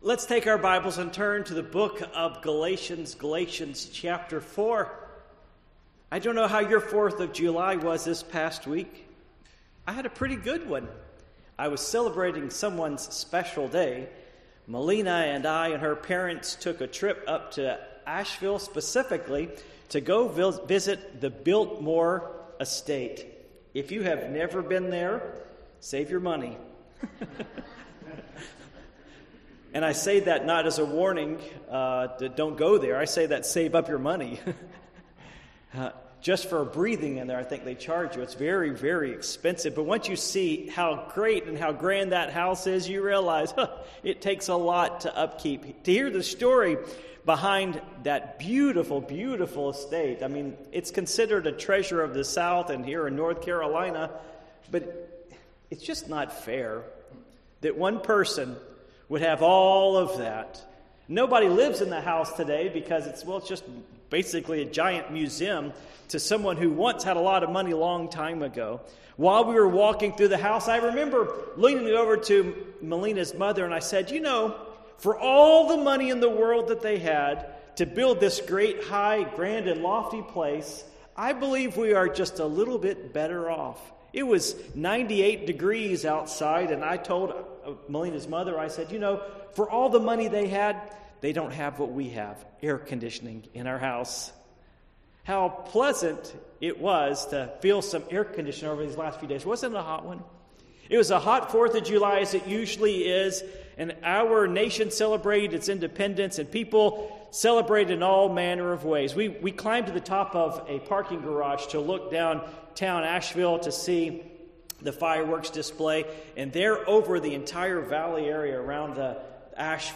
Passage: Galatians 4:1-7 Service Type: Morning Worship